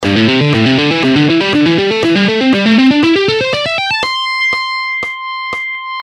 This hybrid picking style combines the best of alternate picking and sweep picking, enabling you to play scales, arpeggios, and complex runs with smoothness and precision.
economy-picking-lesson.gpx-1.mp3